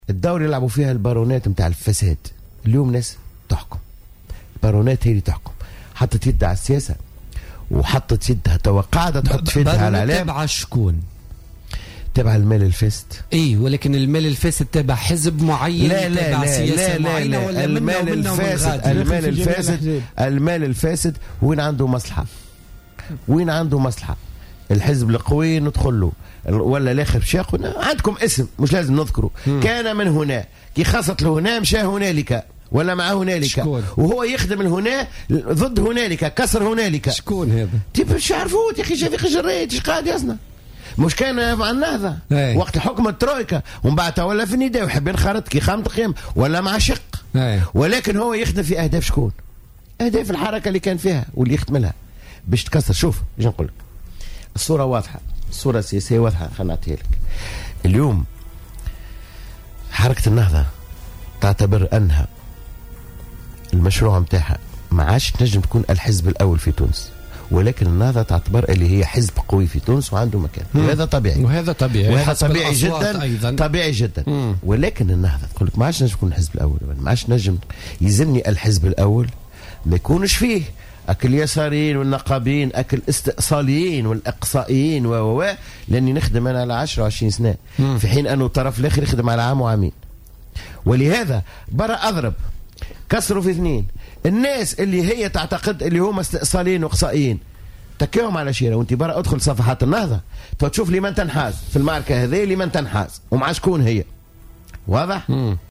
Le secrétaire général d’Al Massar, Samir Taïeb, a indiqué, mercredi 23 décembre 2015, sur Jawhara FM, que l’Etat est gangréné par les barons de la corruption.